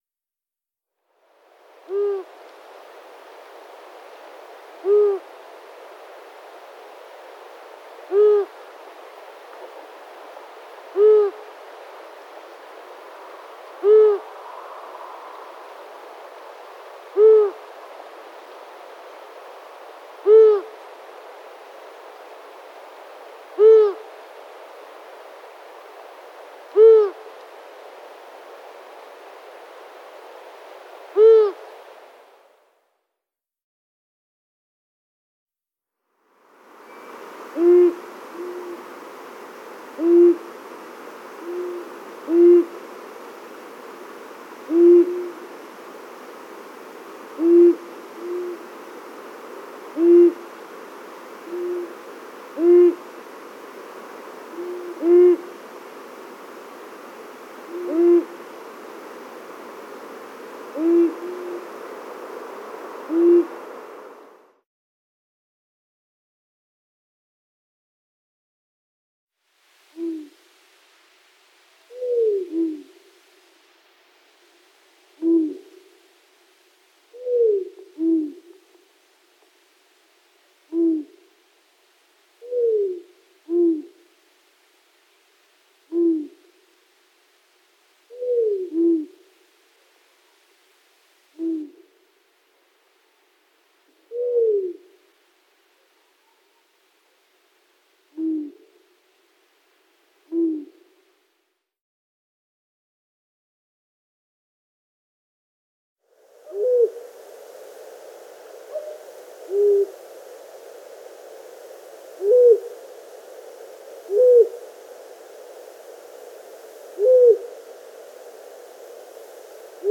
Het mannetje maakt niet heel veel geluid, maar soms roept hij een beetje zeurderig: hoe-oe-oe. Het vrouwtje haar geluid is wat hoger en zachter.
ransuilzang-2.mp3